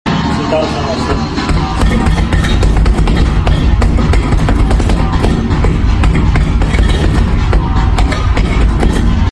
36 galactic punches in 8 sound effects free download